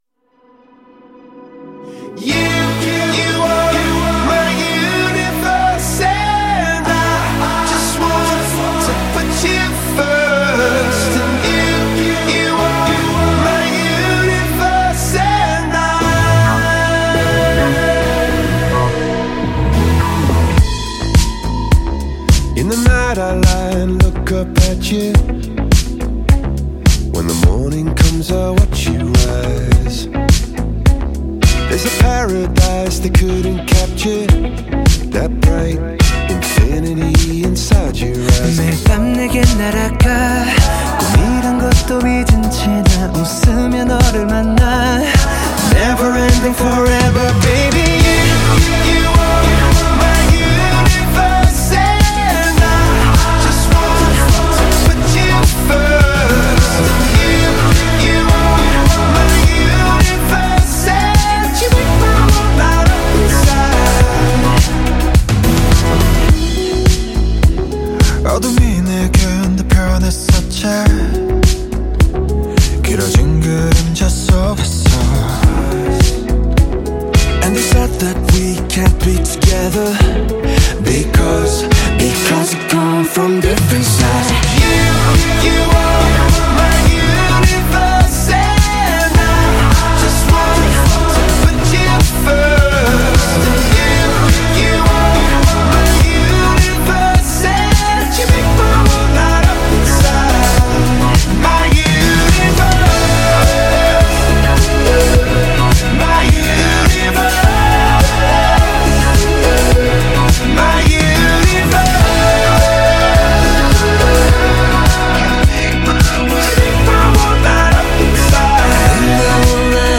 Genre : Alternative.